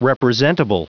Prononciation du mot representable en anglais (fichier audio)
Prononciation du mot : representable